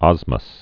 (ŏzməs) also os·mi·ous (-mē-əs)